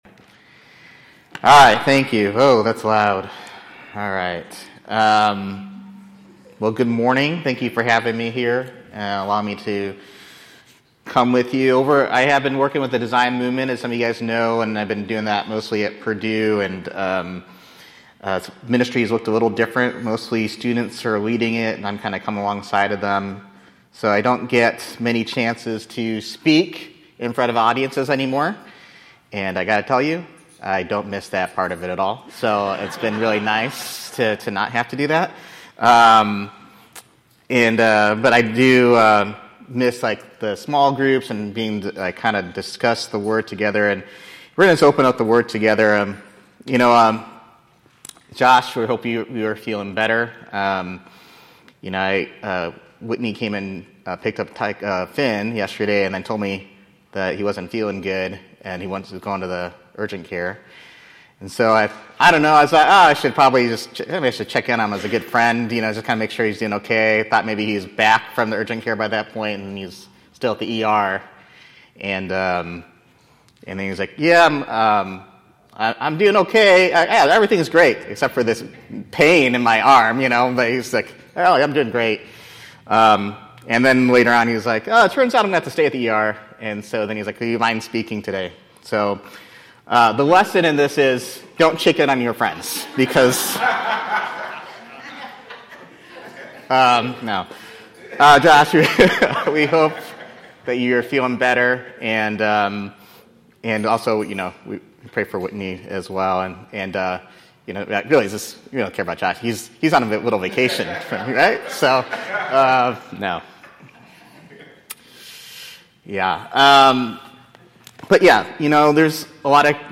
Sermons by City Hope Fellowship